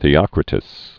(thē-ŏkrĭ-təs) fl. third century BC.